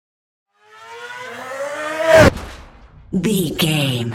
Whoosh fast engine speed
Sound Effects
Fast
futuristic
intense
whoosh
car